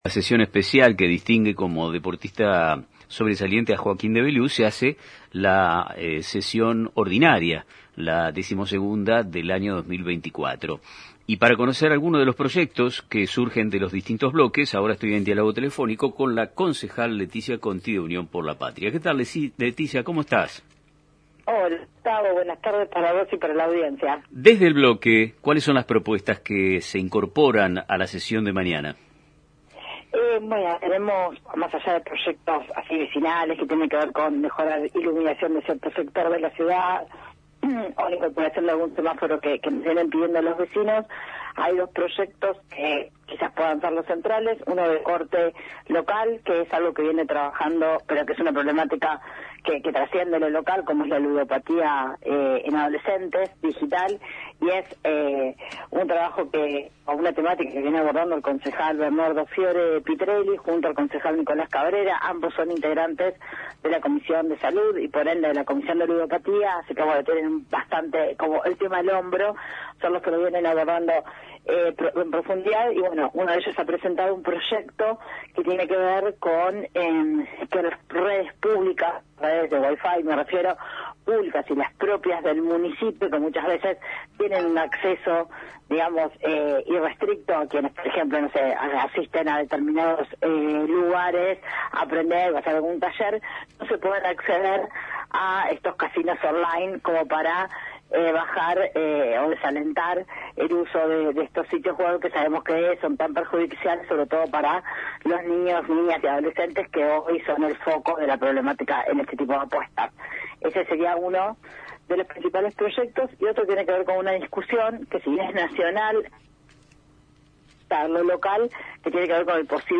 En el marco de la próxima sesión ordinaria del Concejo Deliberante, que se celebrará mañana, la concejal Leticia Conti de Unión por la Patria ofreció detalles sobre los principales proyectos que se tratarán. Durante una entrevista en el programa radial «Nuestro Tiempo de Radio» de Radio Mon Pergamino, Conti abordó temas de relevancia tanto local como nacional.